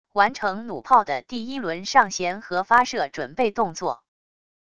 完成弩炮的第一轮上弦和发射准备动作wav音频